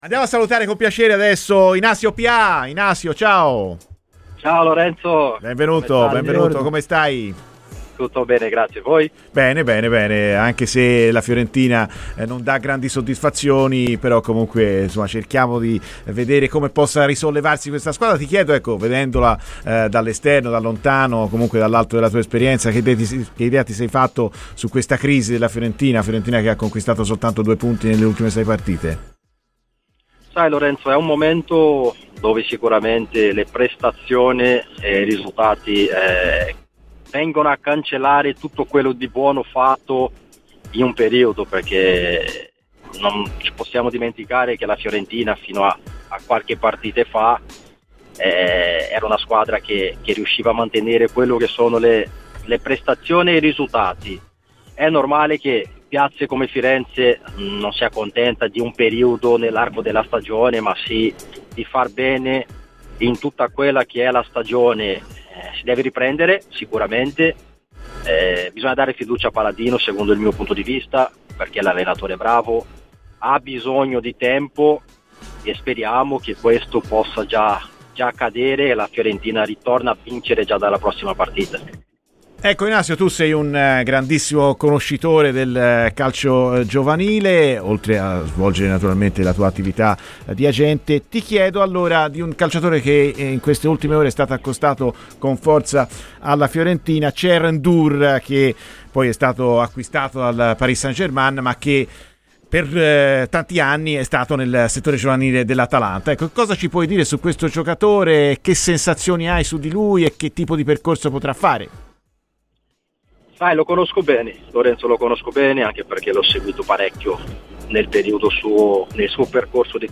Inacio Pià, ex attaccante tra le altre anche dell'Atalanta è Intervenuto a Radio FirenzeViola durante "Viola AmoreMio": "Penso che la Fiorentina stia attraversando un momento non buono, le ultime prestazioni purtroppo stanno cancellando tutto il buono fatto fino ad un mese fa.